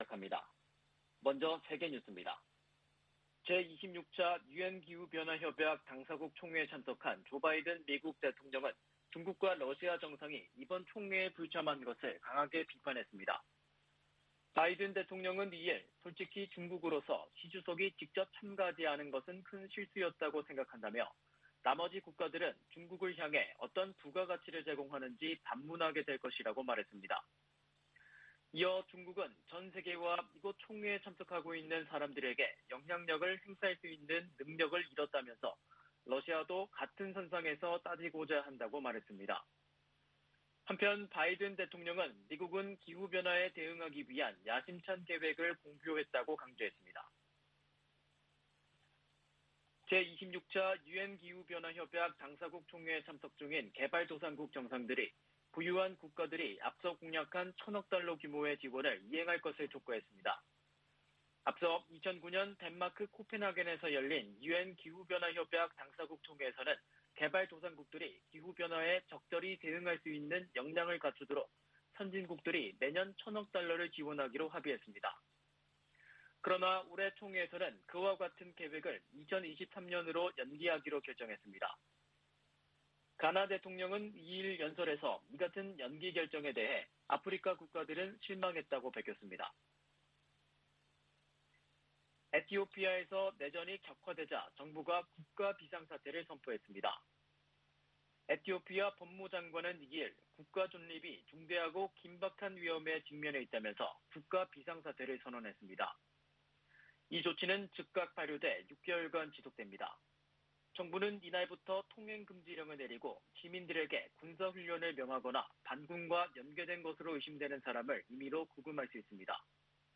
VOA 한국어 '출발 뉴스 쇼', 2021년 11월 4일 방송입니다. 미 연방법원이 중국 기업과 관계자들의 대북제재 위반 자금에 대해 몰수 판결을 내렸습니다. 중국과 러시아가 유엔 안보리에 다시 대북제재 완화 결의안을 제출한 것은 미국과 한국의 틈을 벌리기 위한 것이라고 미국 전문가들이 분석했습니다. 조 바아든 미국 대통령은 유럽 순방서 '더 나은 세계 재건'을 강조했으나, 한반도 관련 주목할 만한 발언은 없었습니다.